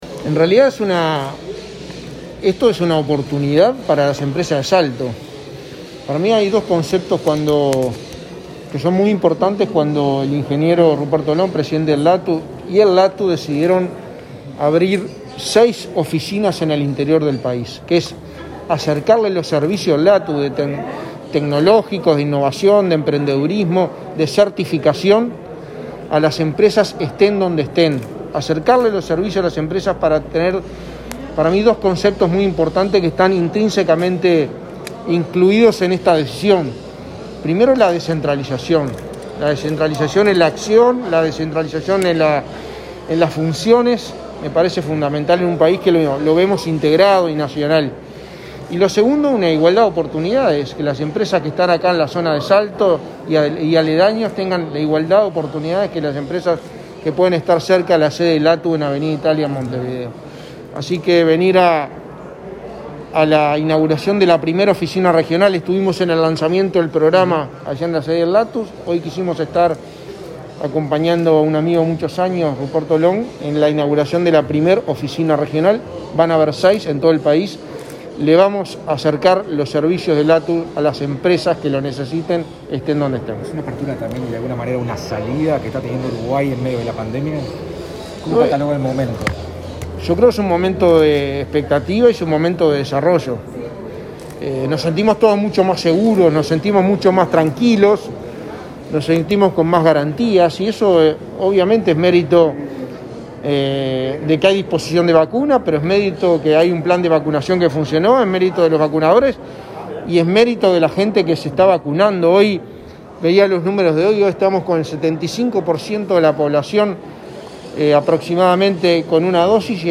Declaraciones del secretario de Presidencia, Álvaro Delgado
Declaraciones del secretario de Presidencia, Álvaro Delgado 06/08/2021 Compartir Facebook X Copiar enlace WhatsApp LinkedIn Delgado, tras participar, este viernes 6 de agosto, de la inauguración de una oficina del Laboratorio Tecnológico del Uruguay (LATU) en Salto Grande, dialogó con medios informativos.